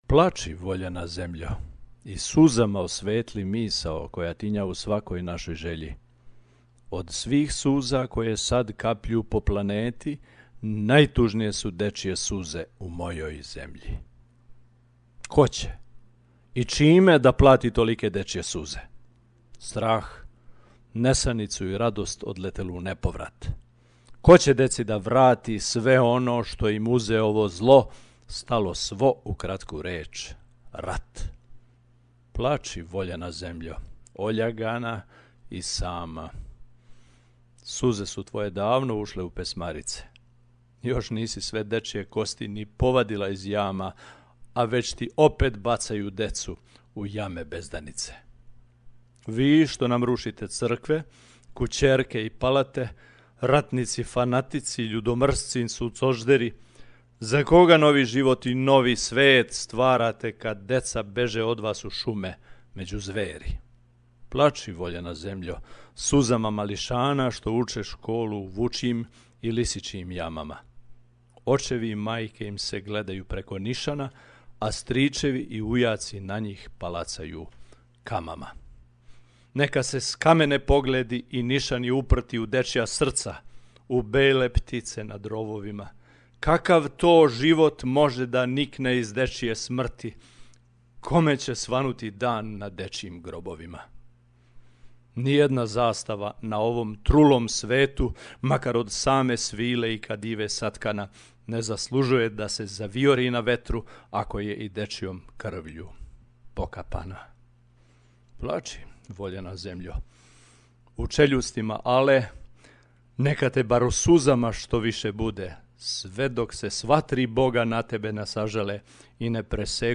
Pesmu kazuje